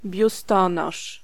Ääntäminen
Synonyymit stanik Ääntäminen Tuntematon aksentti: IPA: [bʲusˈtɔnɔʂ] Haettu sana löytyi näillä lähdekielillä: puola Käännös Ääninäyte Substantiivit 1. brassiere US 2. bra Suku: m .